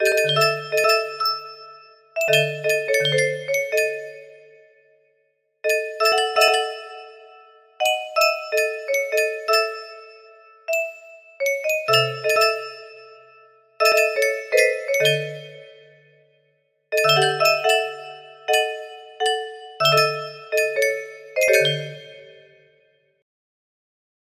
1 music box melody